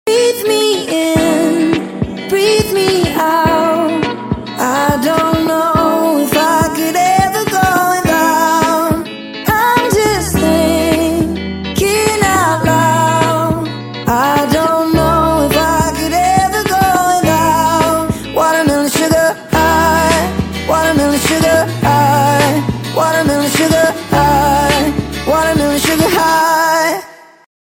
(Sped up)